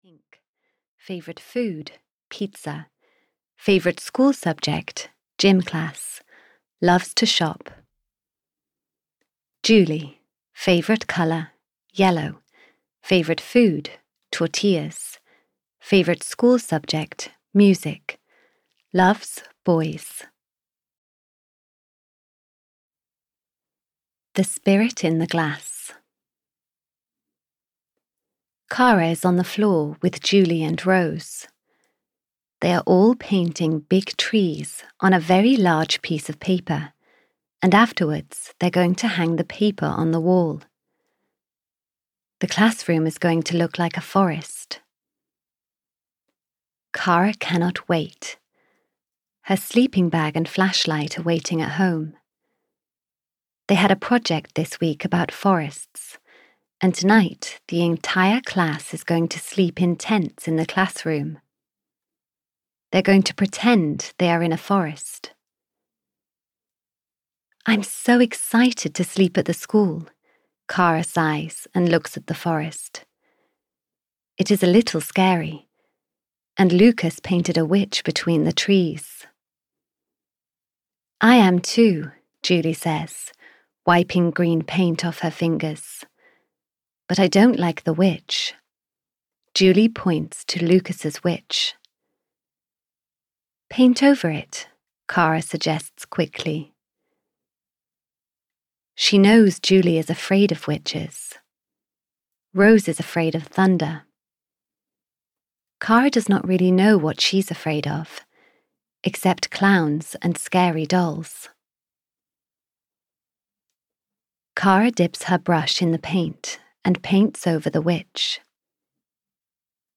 Audio knihaK for Kara 13 - Is Anyone There? (EN)
Ukázka z knihy